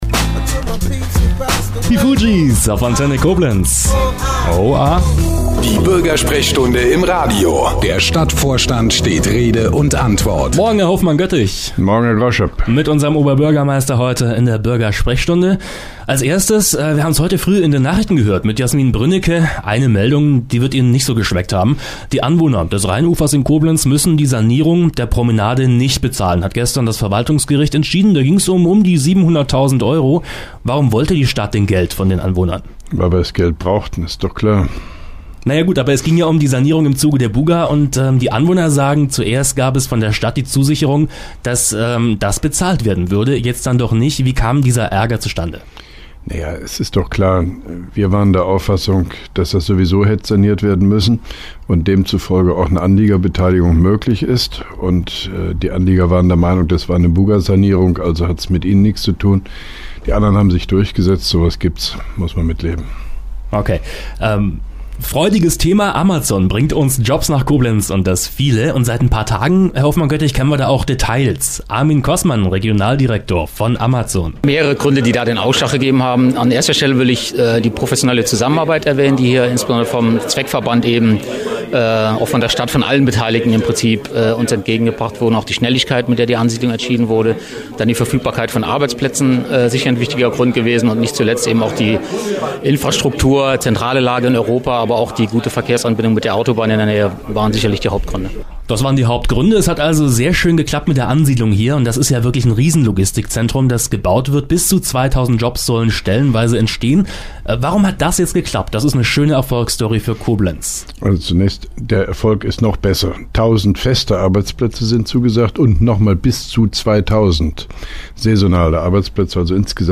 Antenne Koblenz 98,0 am 07.02.2012, ca. 8.25 Uhr (Dauer 04:21 Minuten)